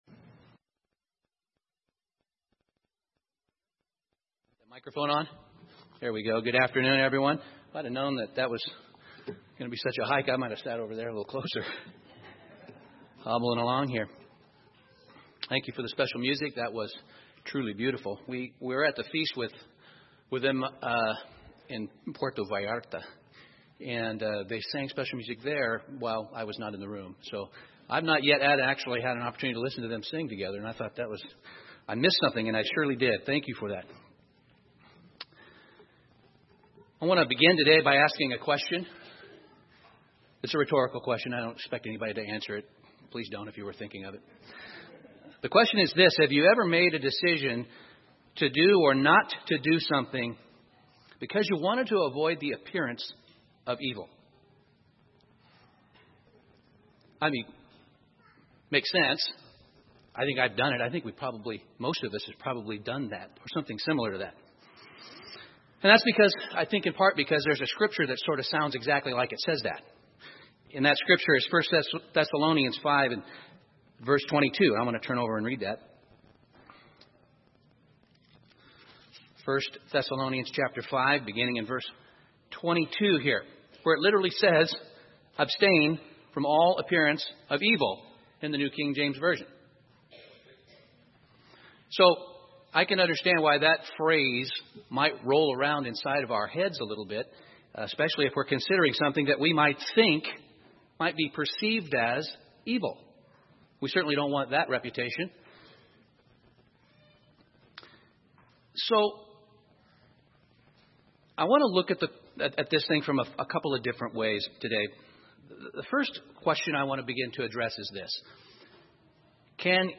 Given in Salem, OR